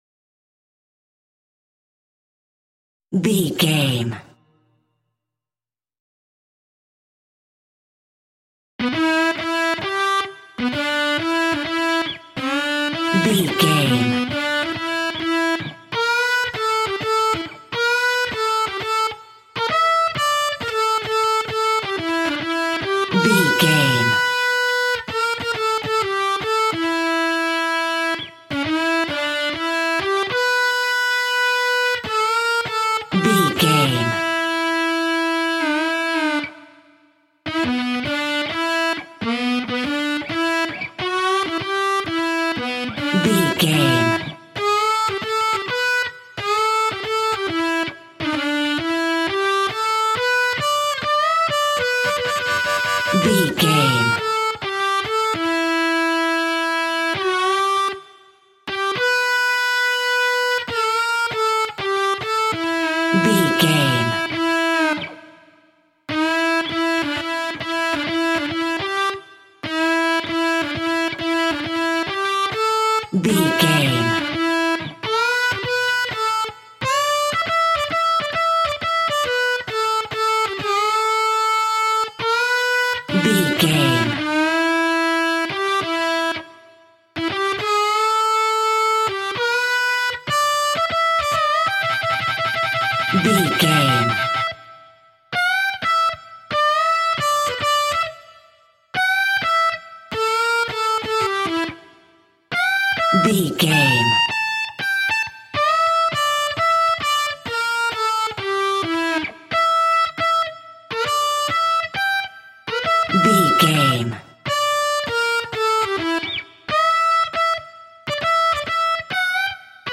Epic / Action
Thriller
Aeolian/Minor
groovy
intense
energetic
electric guitar
classic rock
alternative rock